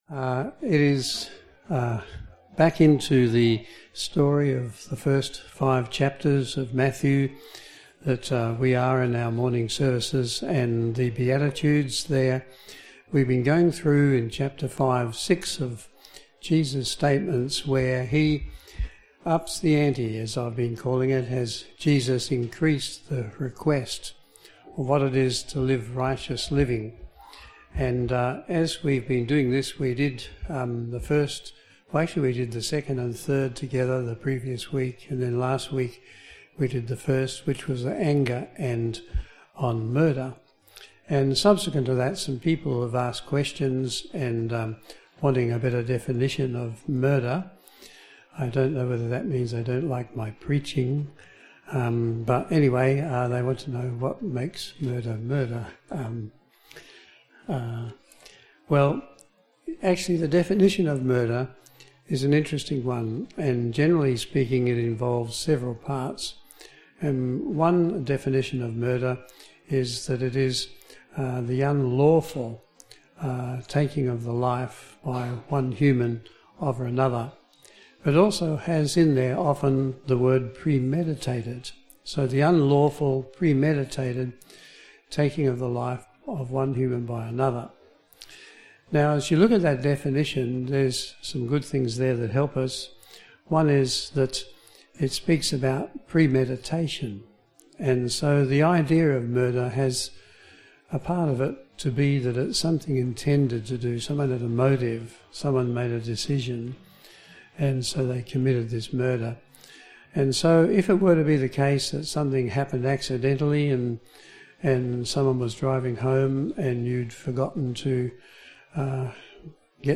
This sermon dives deep into the Beatitudes within the context of Matthew chapters 5 and 6, emphasising Jesus' call for a higher standard of righteousness that goes beyond mere legalism to a heart transformed by sanctification. It explores the nuanced biblical understanding of murder versus manslaughter, underlining the importance of intention and malice in defining wrongful acts. Moreover, it discusses the significance of truthfulness and honesty in our dealings, as exemplified by Jesus' interaction with Nathanael in the Gospel of John.